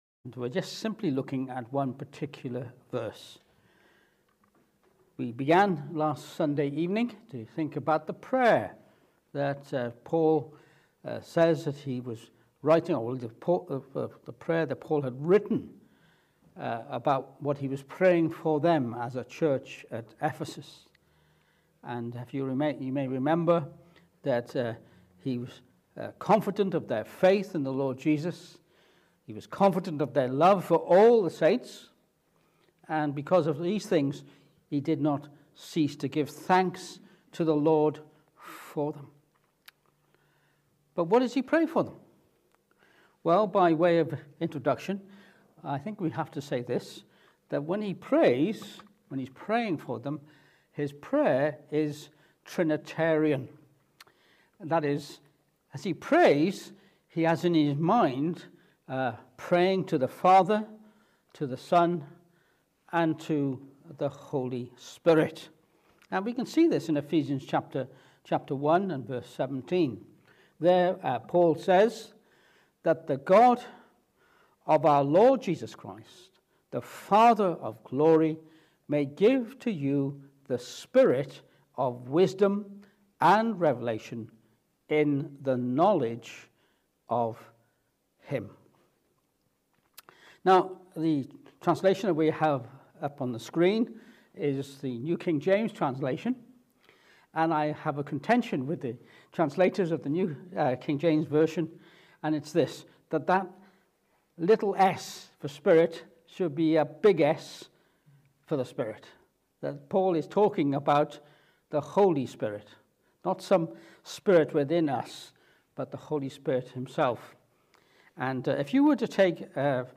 Ephesians 1:17 Service Type: Evening Service In Ephesians 1:17